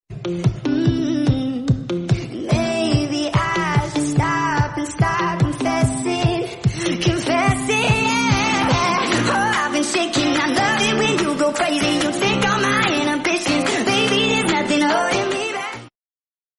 #8daudio
#8dmusic